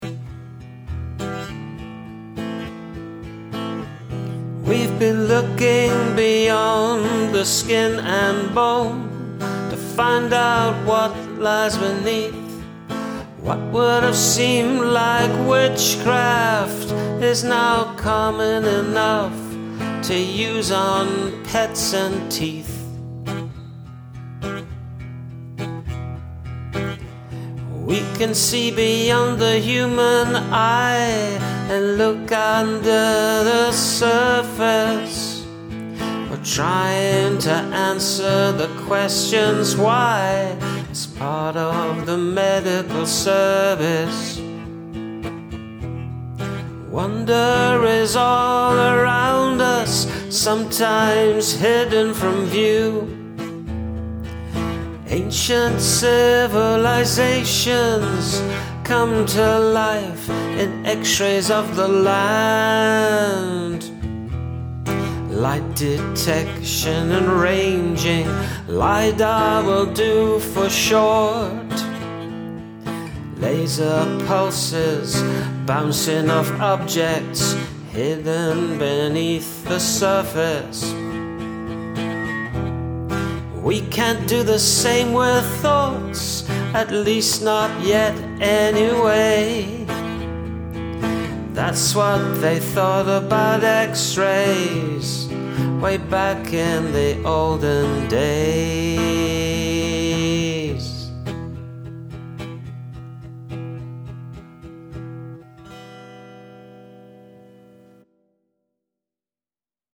Wonderful upbeat song, singing and playing ✨ 🎶🎵✍